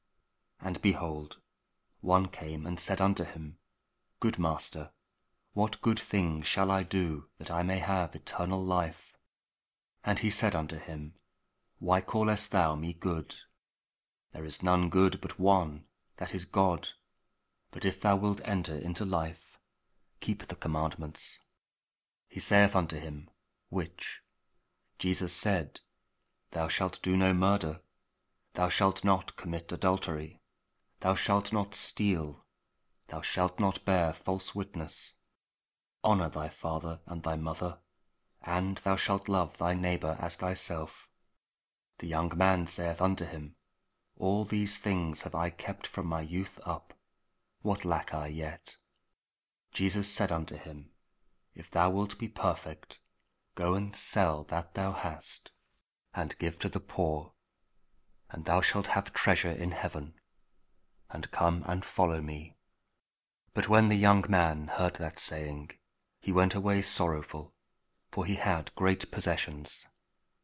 Matthew 19: 16-22 | King James Audio Bible | KJV | King James Version